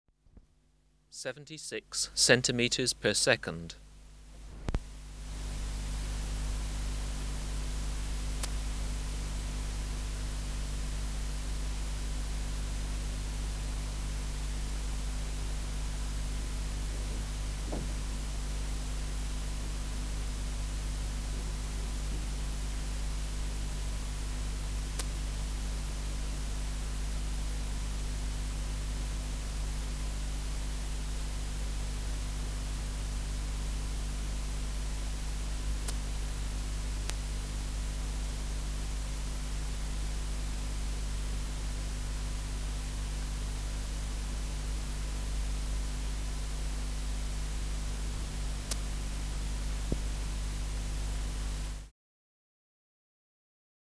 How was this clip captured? Recording Location: BMNH Acoustic Laboratory Reference Signal: 1 kHz for 10 s Substrate/Cage: small recording cage Microphone & Power Supply: Sennheiser MKH 405 Distance from Subject (cm): 11 Filter: Low pass, 24 dB per octave, corner frequency 20 kHz